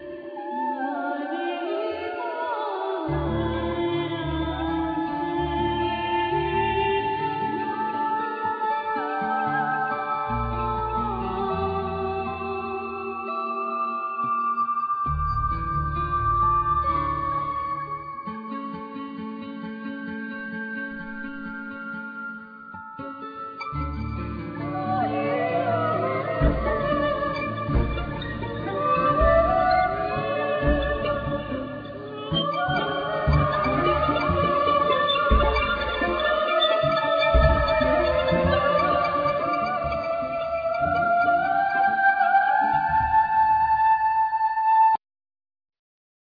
Keyboards
Voice
Drum programming
Guitar
Bass
Piano
Violin